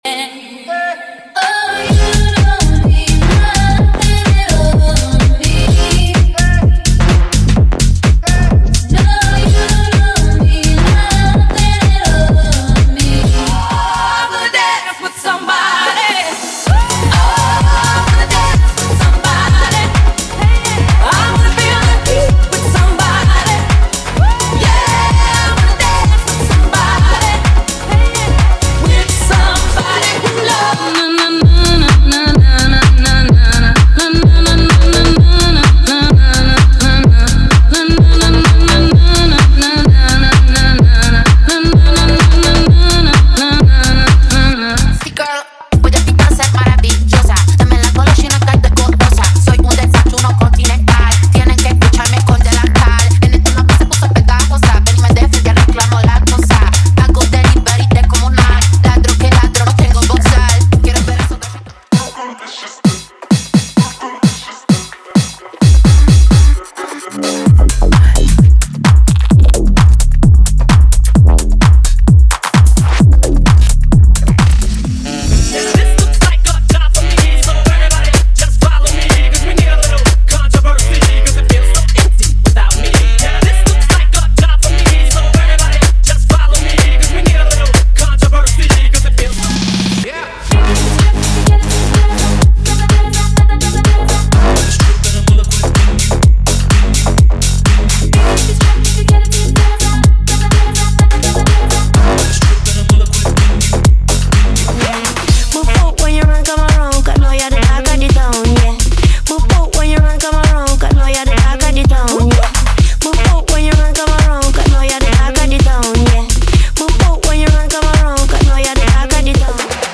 Sem Vinhetas